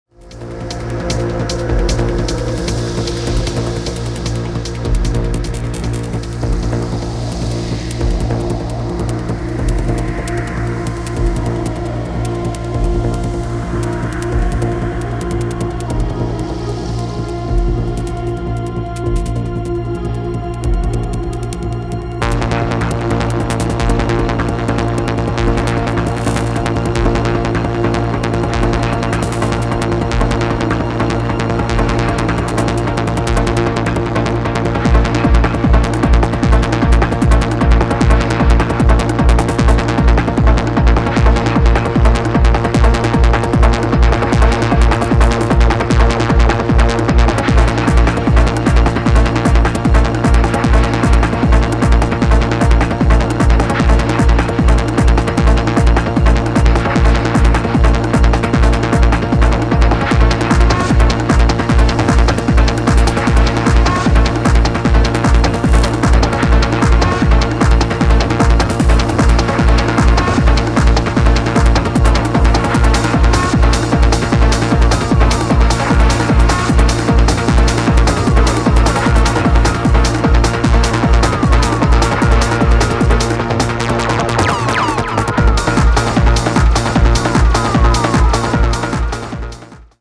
初期のスローモーなグルーヴからは装いを変えて、今回は速いです。
持ち味のトライバルなパーカッションも相まって非常にグルーヴィー、強力無比です。